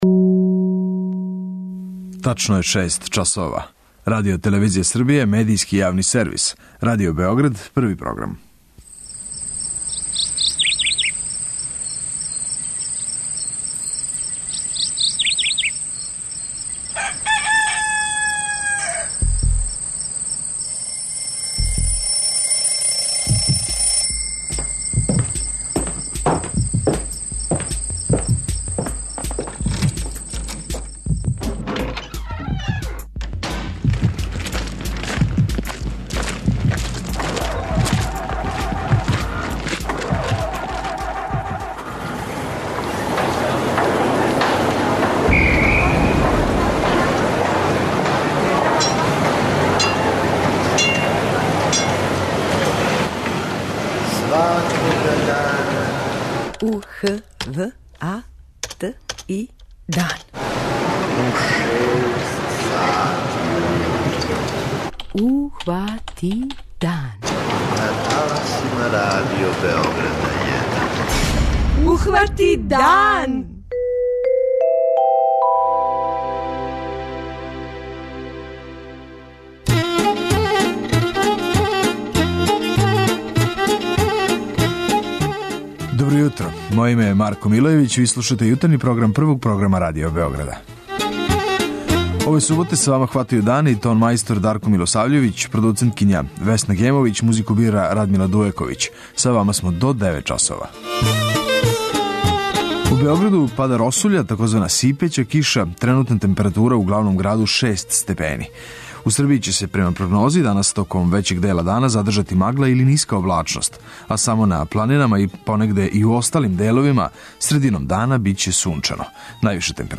У оквиру емисије емитујемо: 06:03 Јутарњи дневник; 06:35 Догодило се на данашњи дан; 07:00 Вести; 07:05 Добро јутро децо
преузми : 85.94 MB Ухвати дан Autor: Група аутора Јутарњи програм Радио Београда 1!